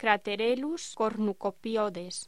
Locución: Craterellus cornucopioides
voz